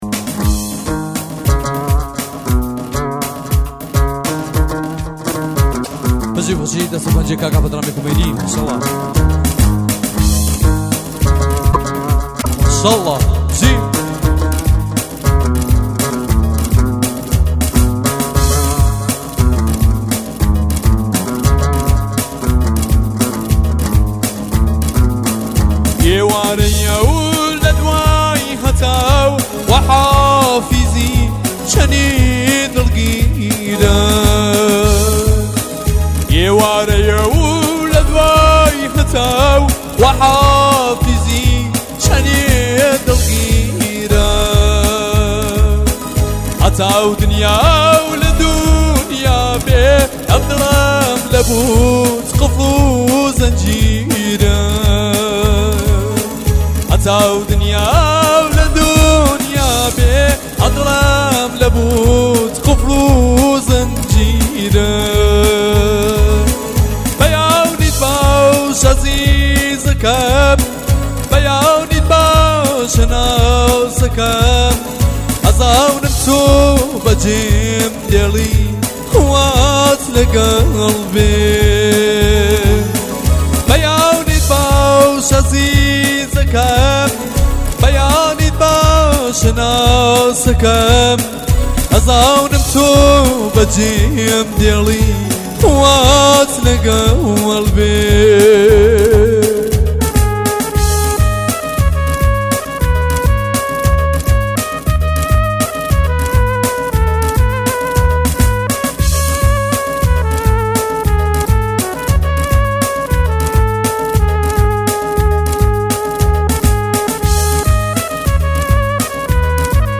آهنگ لری